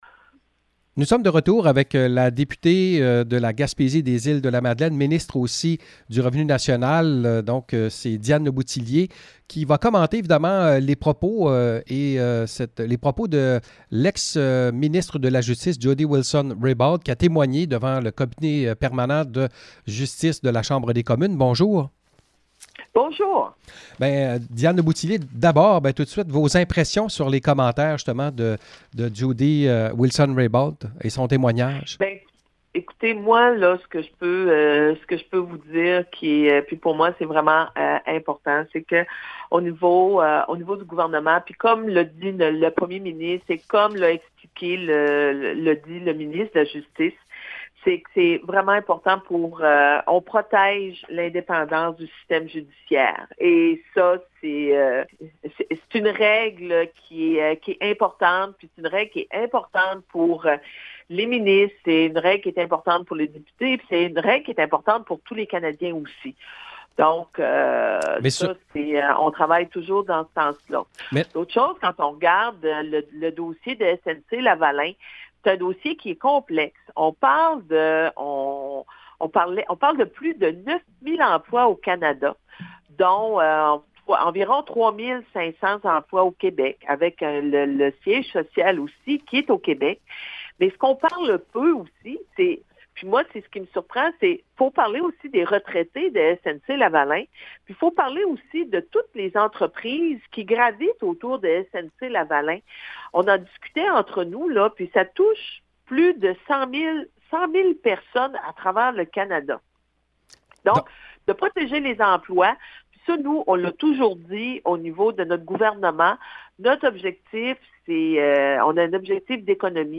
Entrevue avec Diane Lebouthillier: